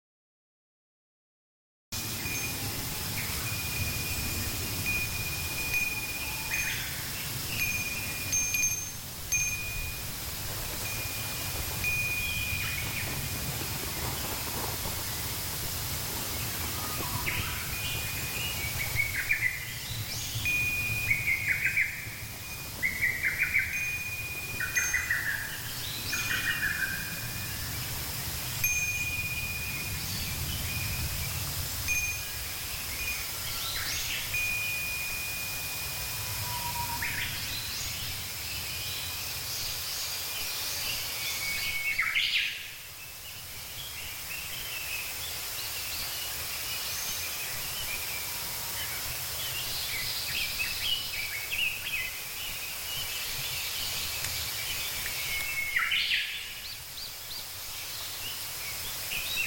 耳畔不时飘来清亮悠扬的风铃声，仿佛在不远处轻轻摇曳。
结构很简单、两片铜片相互悬挂，随风轻触，就能奏出这样的清脆声响。
风鈴雀乌声.mp3